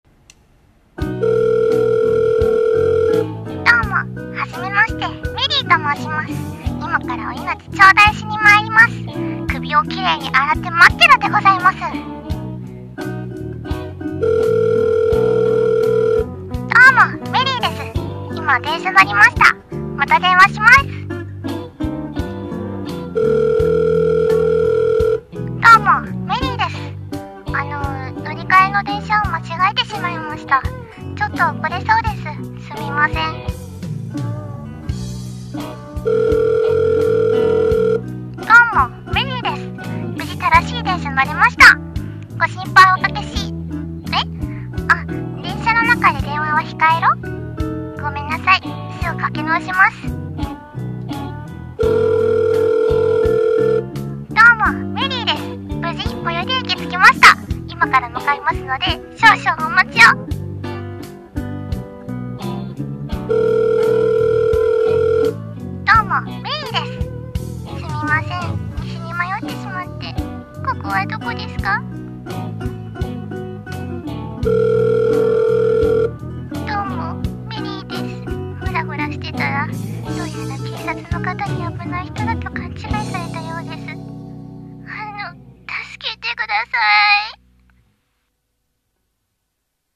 【ギャグ声劇台本】どうも、メリーです。